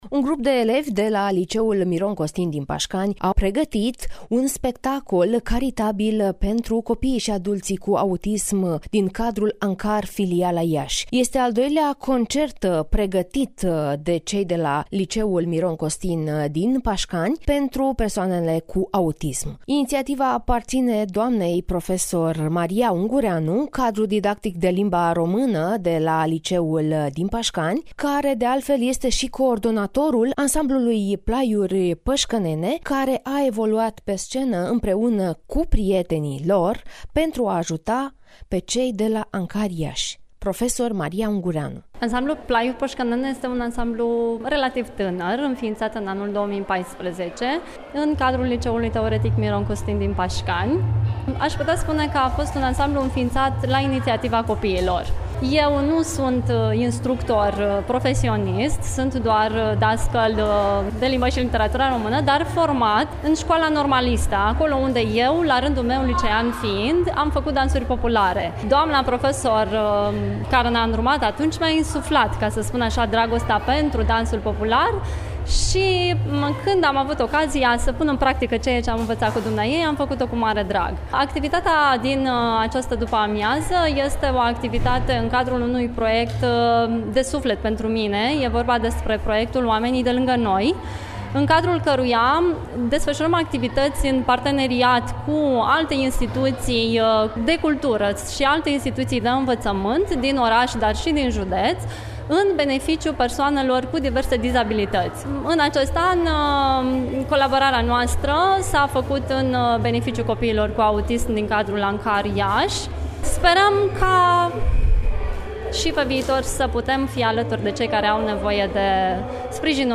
(INTERVIU) Ansamblul de dansuri populare Plaiuri Păşcănene a organizat spectacol pentru a ajuta copiii cu autism